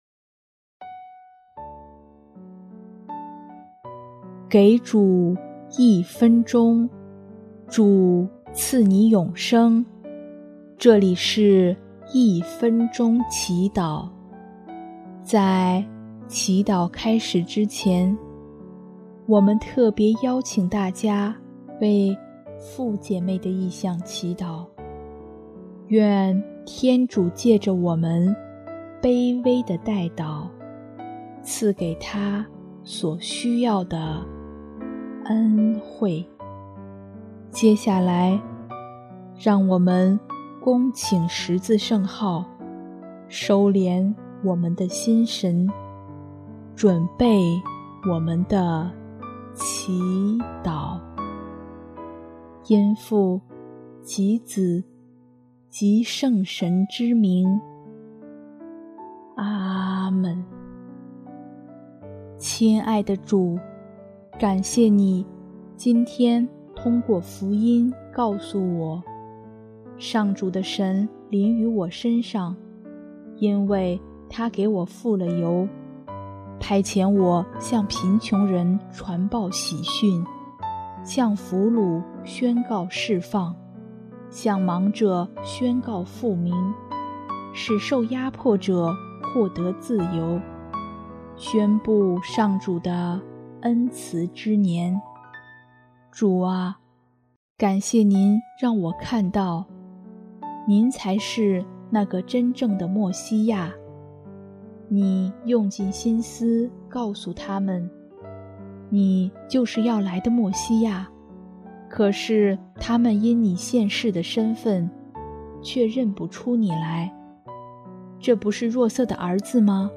音乐：主日赞歌